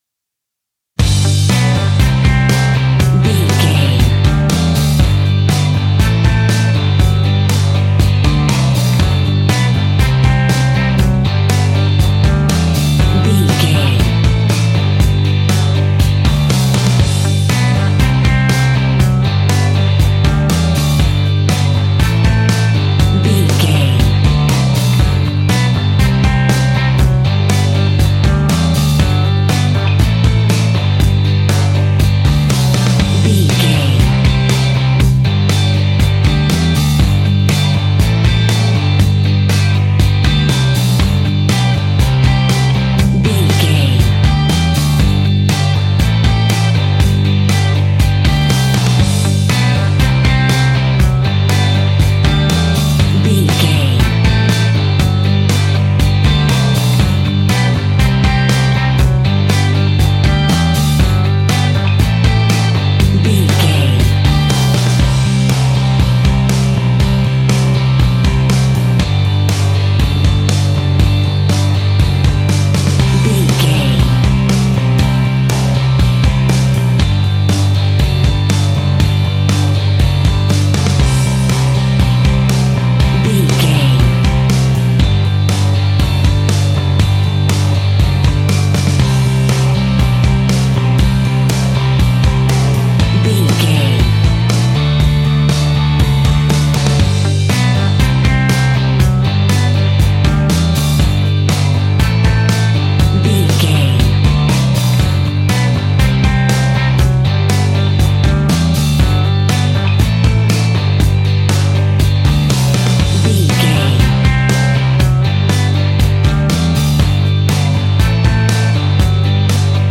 Ionian/Major
D
fun
energetic
uplifting
instrumentals
upbeat
rocking
groovy
guitars
bass
drums
piano
organ